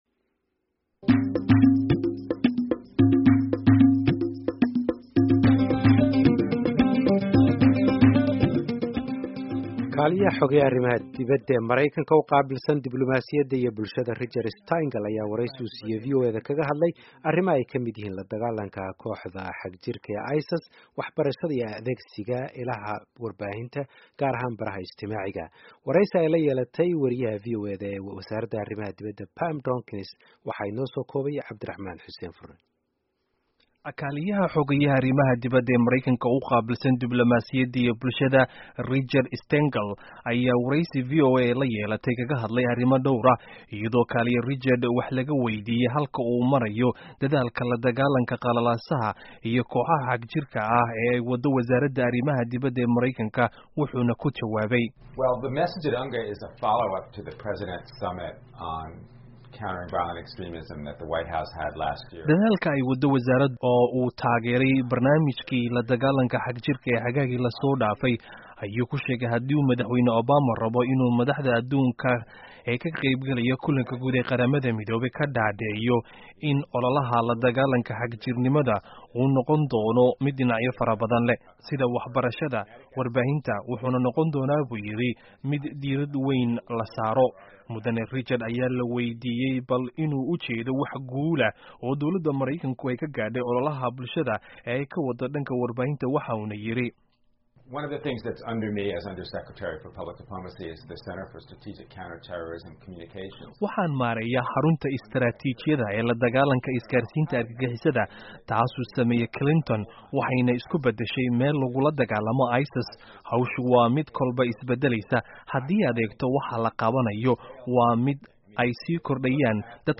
Wareysi: Richard Stengel